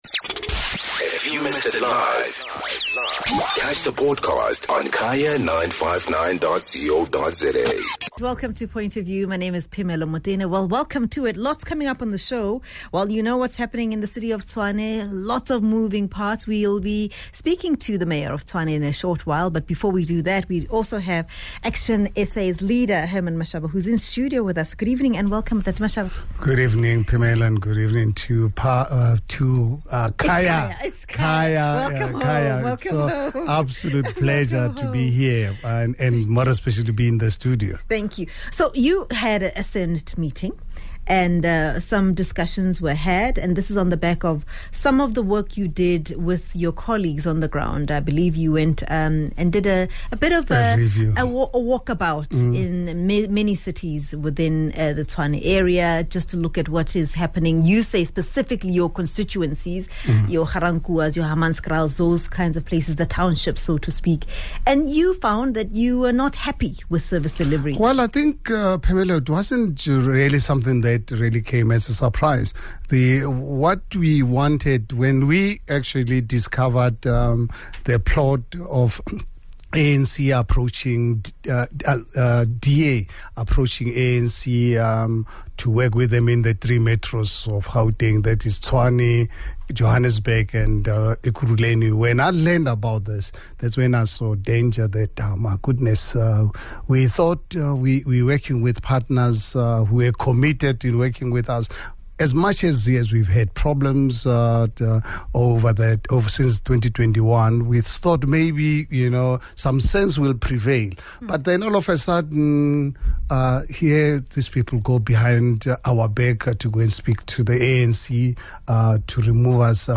Tshwane Mayor Cilliers Brink joins in on the conversation. The DA is anxiously waiting to hear which direction the ANC believes the Tshwane government should take.